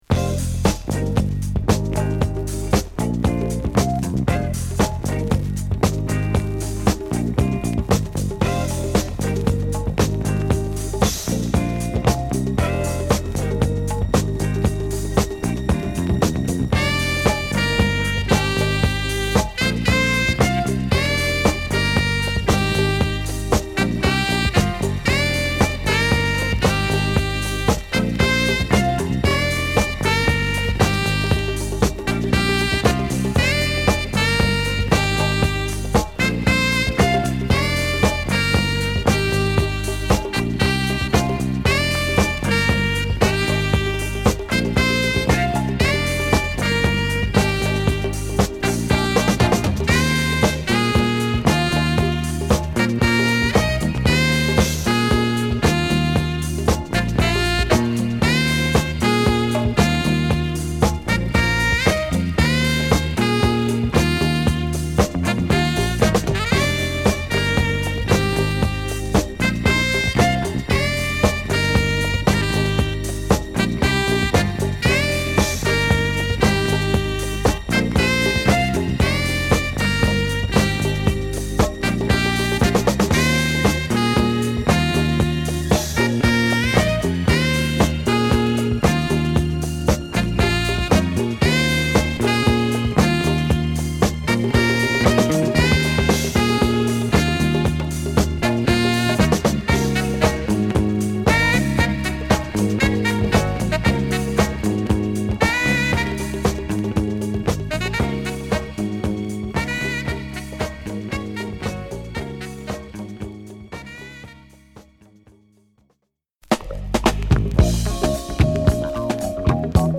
パーカッションが小気味良く鳴るジャズファンクブギー
＊B面ノイズ小